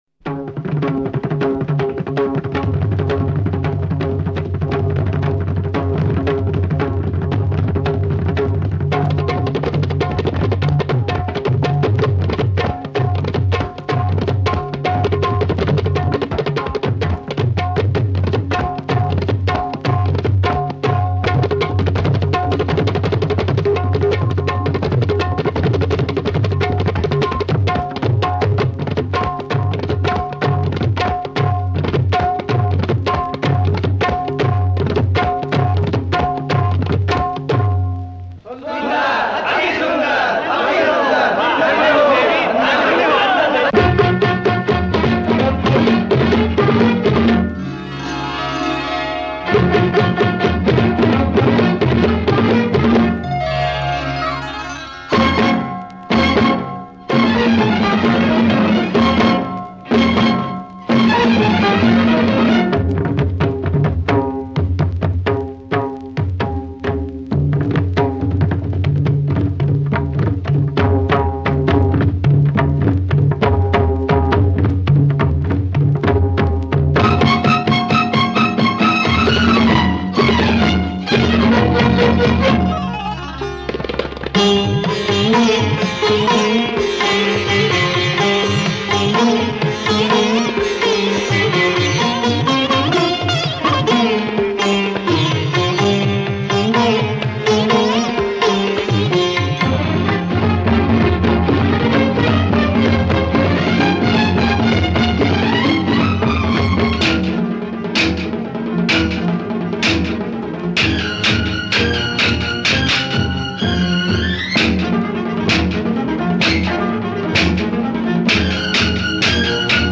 Artist: Instrumental